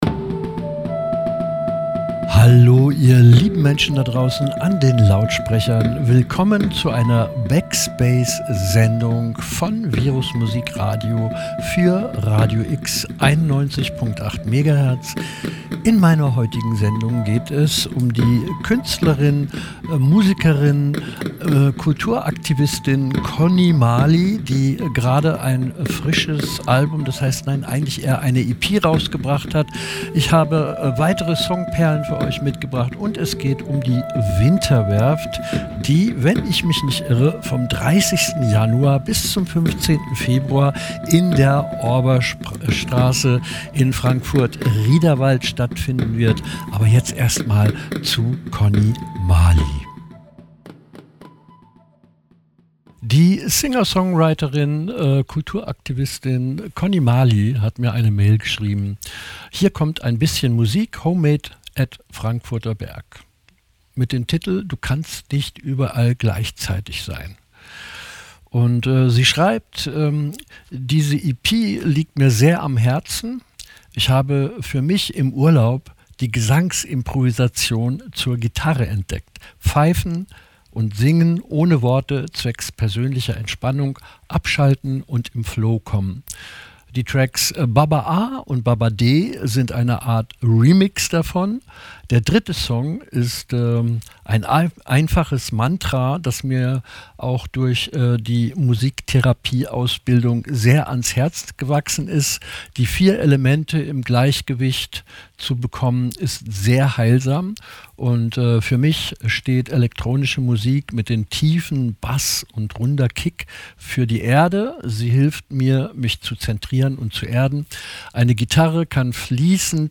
Ein Radiobeitrag